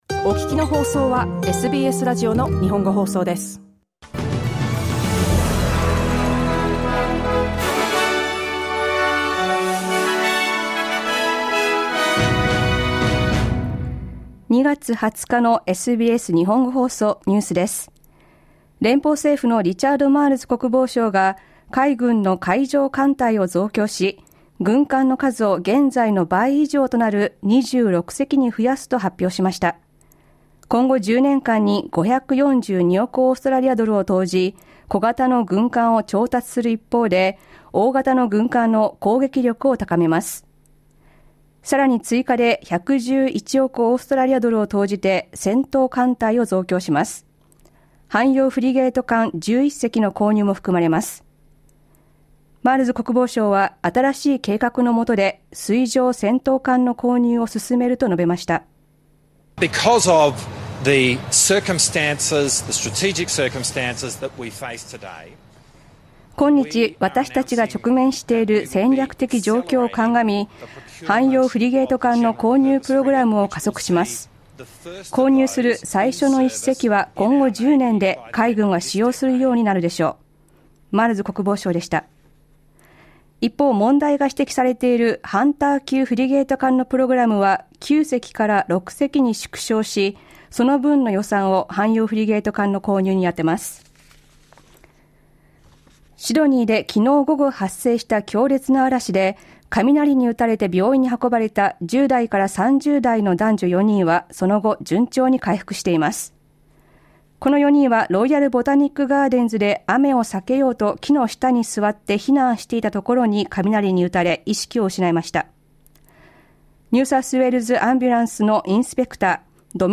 午後１時から放送されたラジオ番組のニュース部分をお届けします。